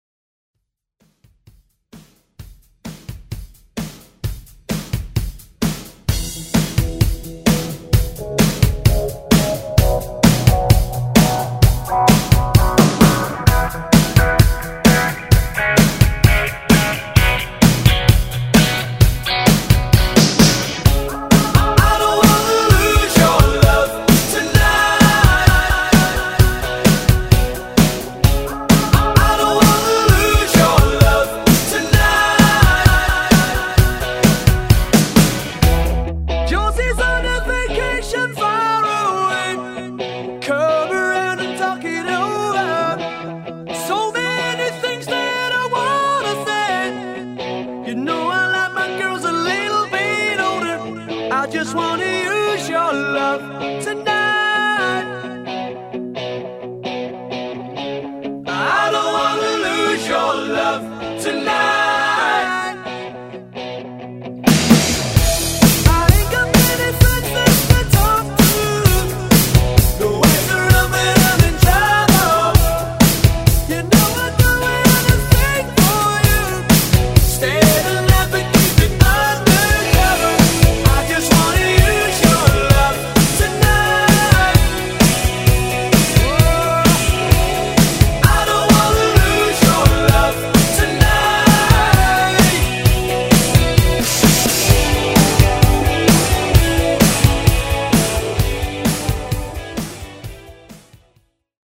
Genres: 80's , RE-DRUM , ROCK
Clean BPM: 130 Time